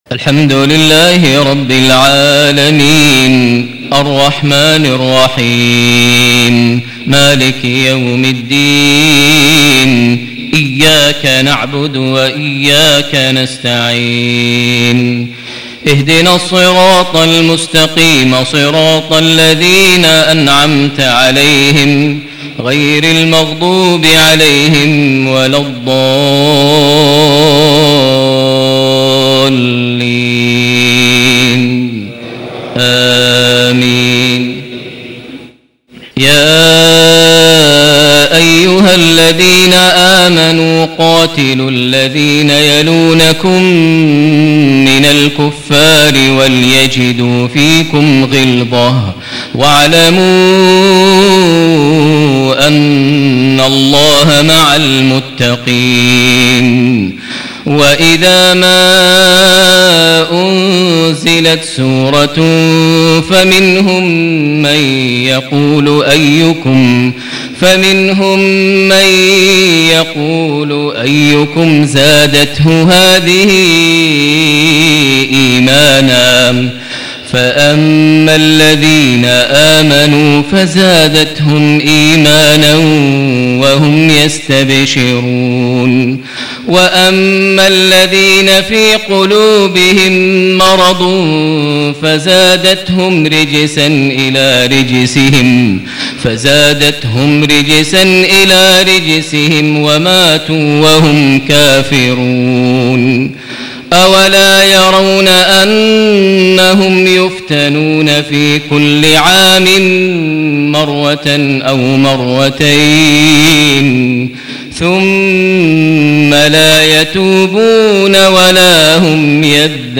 صلاة المغرب ٢٣ شعبان ١٤٣٨هـ خواتيم سورة التوبة > 1438 هـ > الفروض - تلاوات ماهر المعيقلي